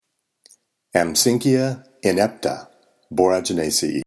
Pronunciation:
Am-sínck-i-a in-ép-ta